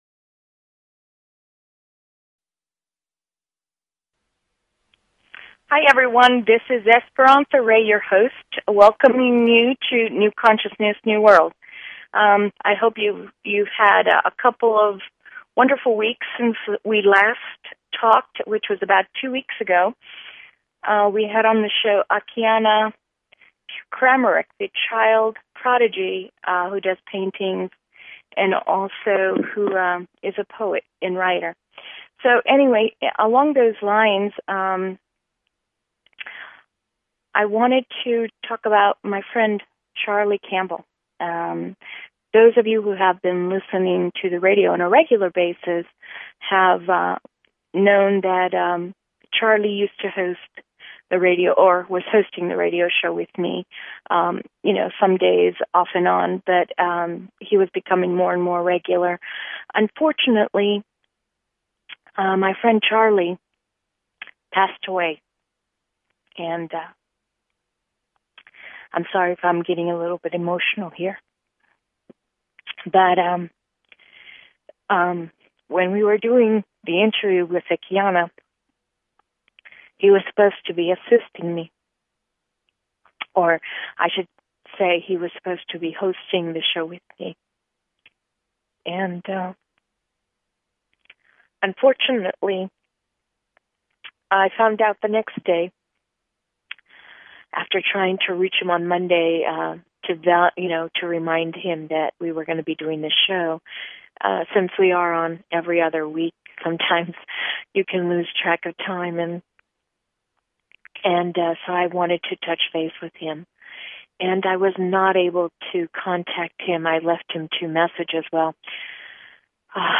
Talk Show Episode, Audio Podcast, New_Consciousness_New_World and Courtesy of BBS Radio on , show guests , about , categorized as